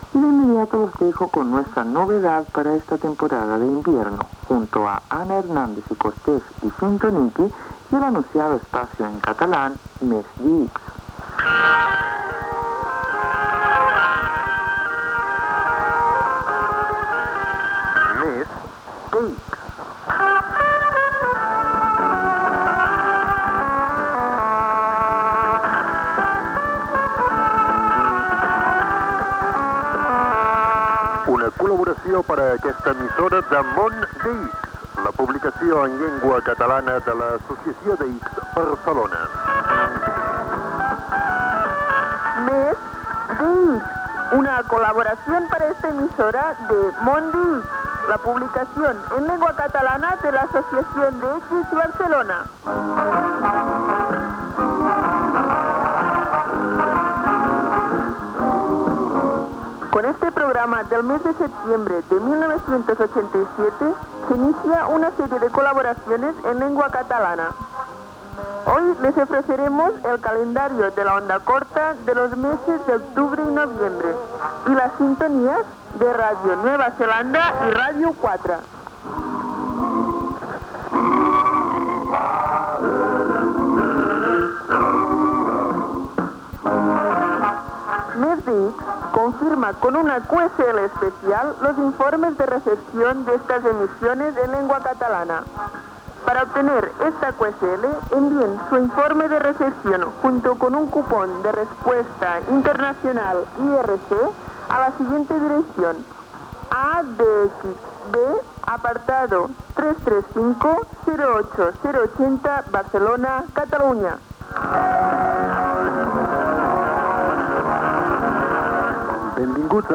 Calendari de l'ona curta, sintonies de Ràdio Nova Zelanda i Ràdio 4.
Divulgació